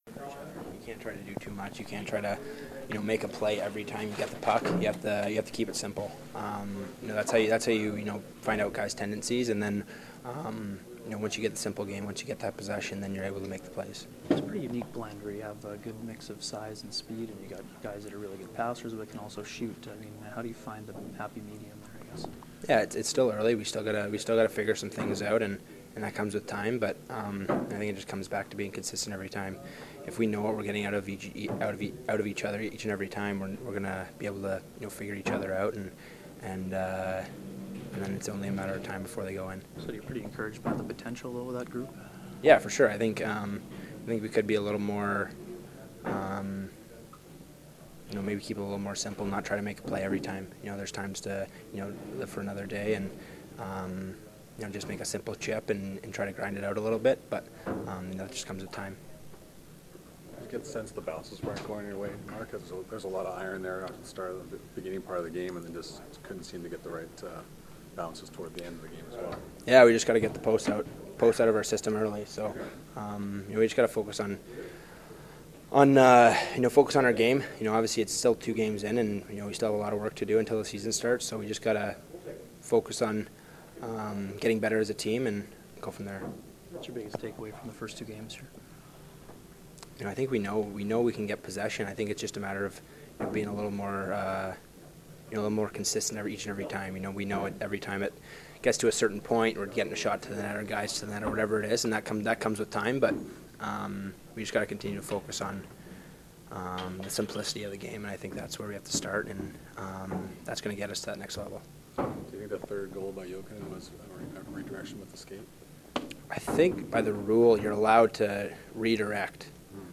Post-game from the Jets dressing room as well as from Coach Maurice.